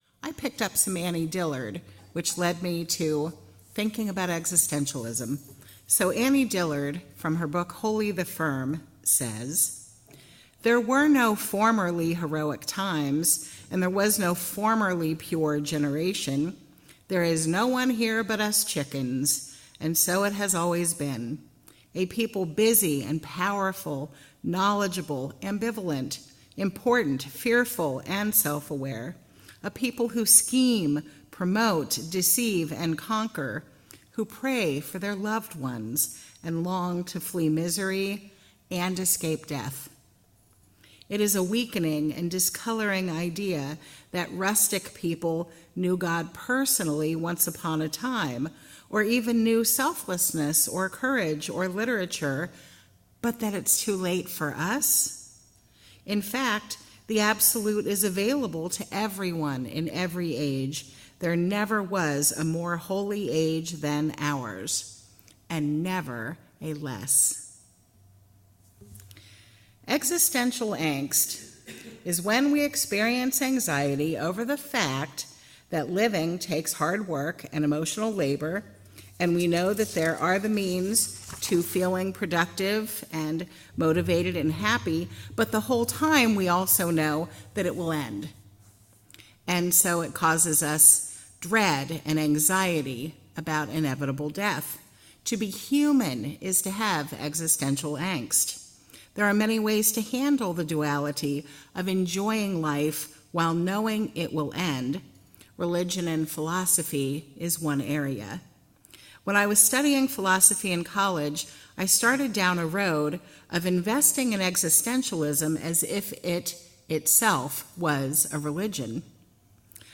Reading: from Holy the Firm by Annie Dillard.
Sermon: Existentialism and Terra Cotta Soldiers